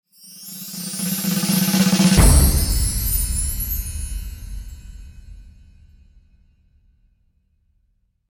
Drum Roll Magic Poof Transition Sound Effect
This drum roll magic poof sound effect works perfectly as a logo ident with a deep hit and clean transition. Use this sudden sound to build tension and create a strong reveal for videos, intros, and branding.
Genres: Sound Logo
Drum-roll-magic-poof-transition-sound-effect.mp3